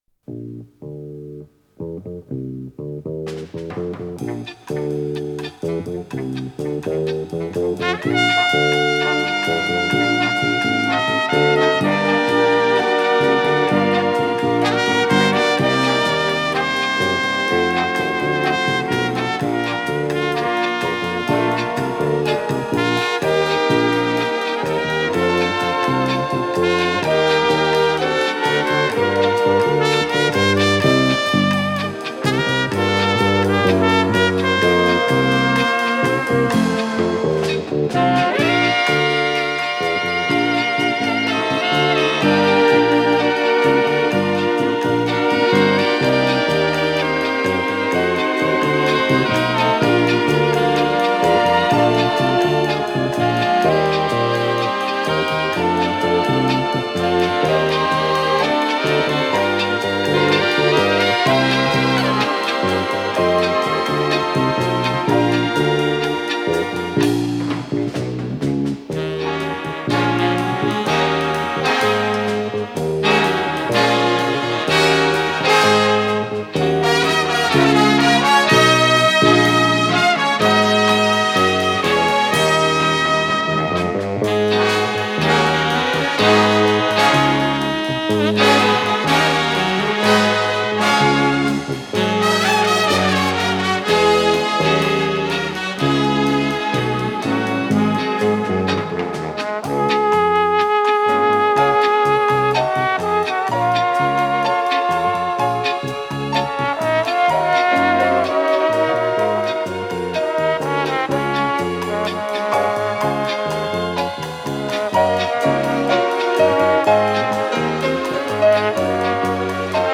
с профессиональной магнитной ленты
ПодзаголовокПьеса для эстрадного оркестра, си бемоль мажор